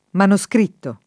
[ mano S kr & tto ]